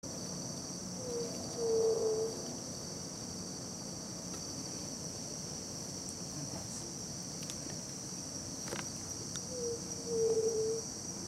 Yerutí Común (Leptotila verreauxi)
Nombre en inglés: White-tipped Dove
Fase de la vida: Adulto
Localidad o área protegida: Reserva Ecológica Costanera Sur (RECS)
Condición: Silvestre
Certeza: Vocalización Grabada